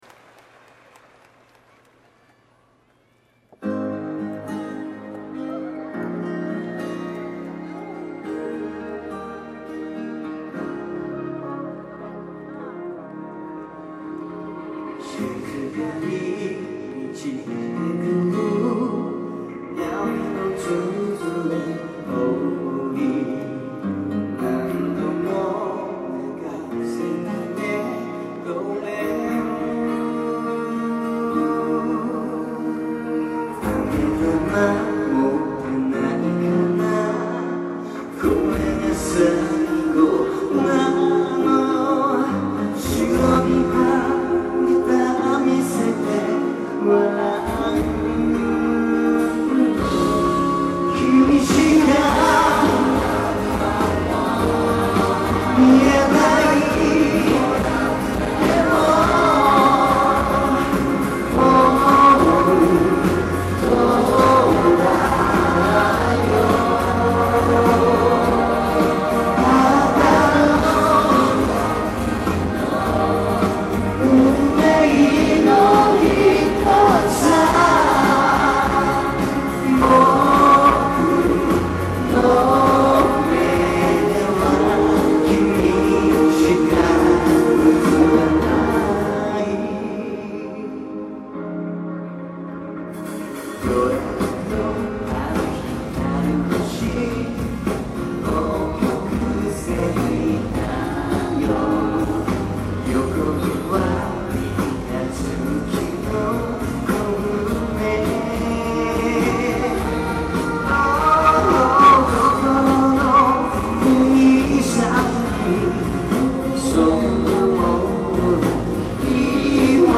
그래서 여름에 갔을 때 파일을 뒤져보니까 여긴 애드립이 없었네요 ; ω;///
근데 그것보다 놀란건 녹음 상태가......;;. 나름 그 때는 이거 잘 해 온거라고 생각했었는데
이번에 한 것들이랑 비교해보니 막 고음에서 튀기는거 있고ㅋㅋ 베이스 쿵쿵 거리고ㅋㅋㅋㅋ